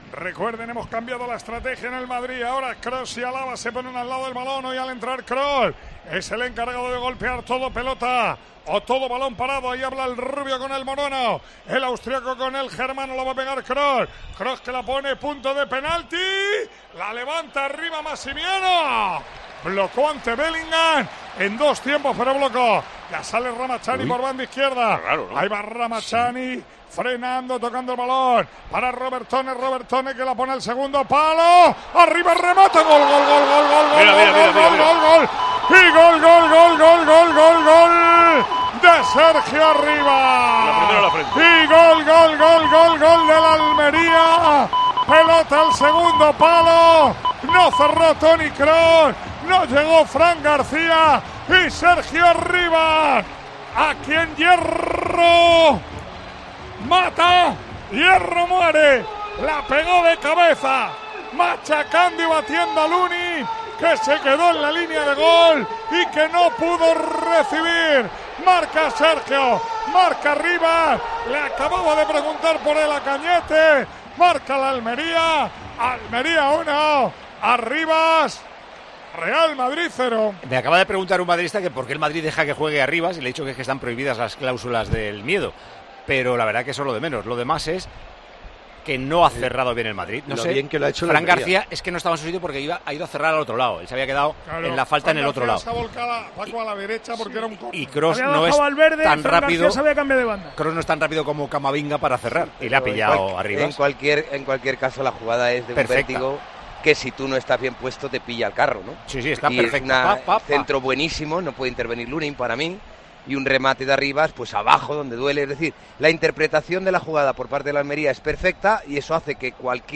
Así vivimos en Tiempo de Juego la retransmisión del Almería - Real Madrid